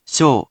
In romaji, 「しょ」 is transliterated as 「sho」which sounds sort of like the word「show」.